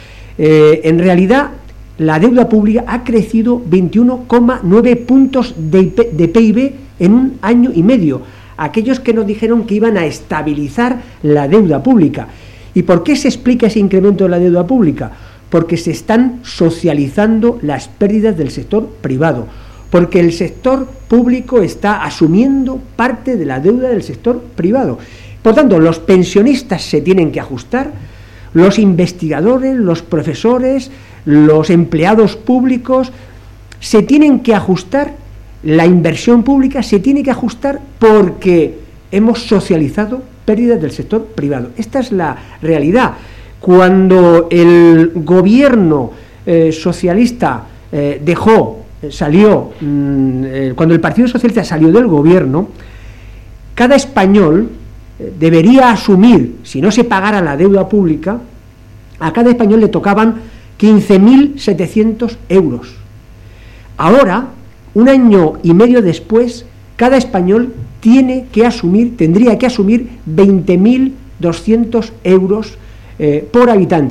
Fragmento de la rueda de prensa de Pedro Saura en la que acusa a Rajoy de socializar las pérdidas del sector privado 13/09/2013